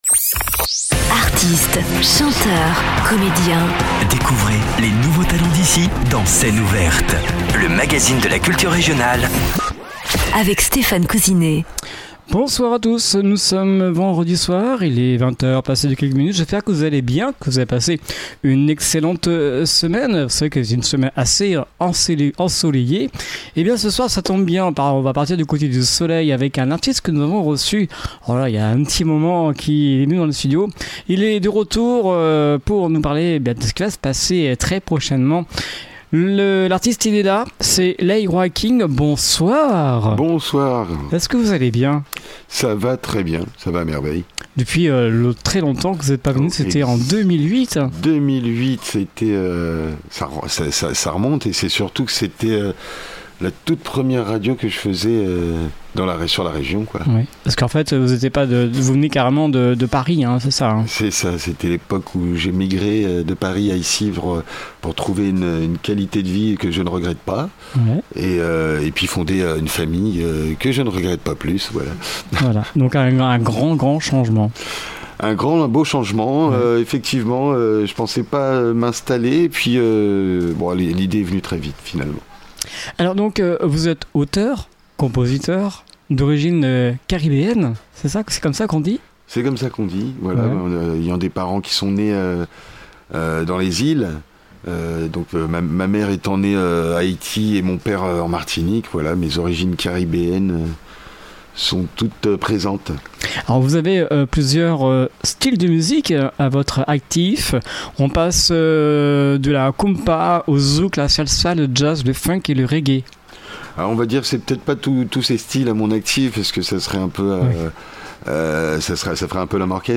Scène ouverte